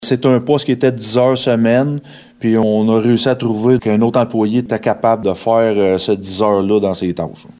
Voici le maire Mathieu Caron :